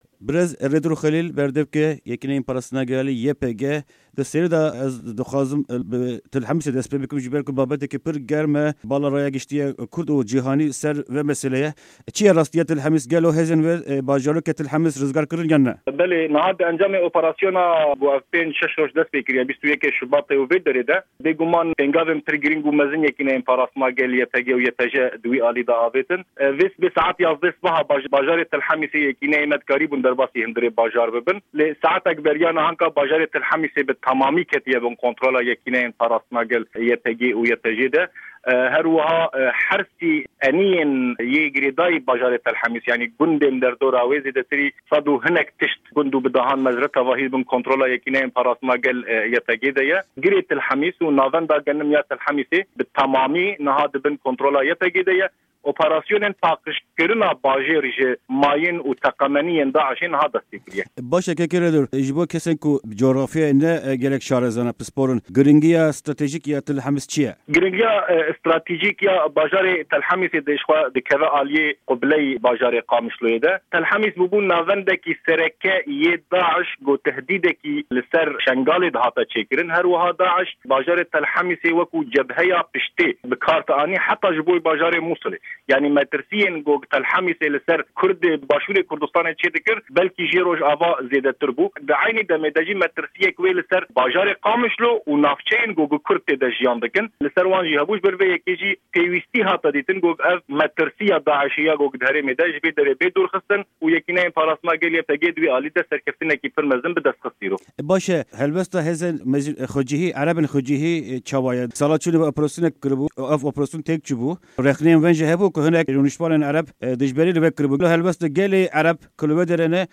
Di hevpeyvîneke taybet ya Dengê Amerîka de